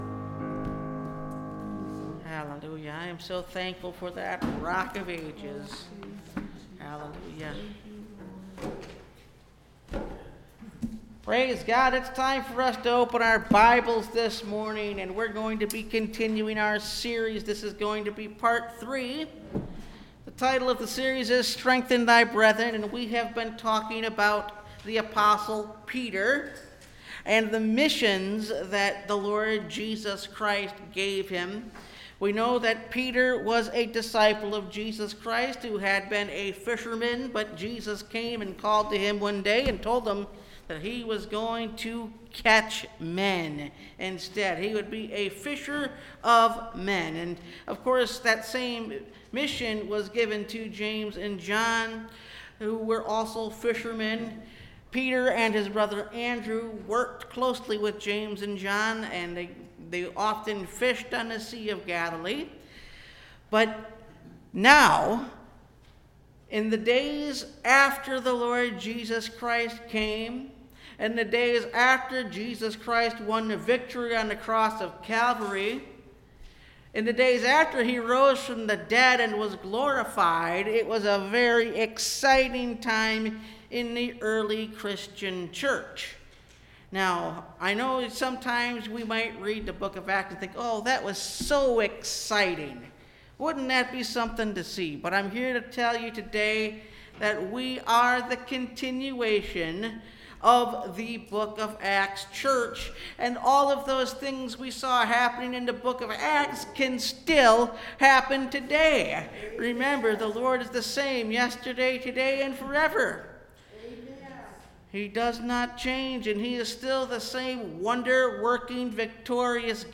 Strengthen Thy Brethren – Part 3 (Message Audio) – Last Trumpet Ministries – Truth Tabernacle – Sermon Library
Service Type: Sunday Morning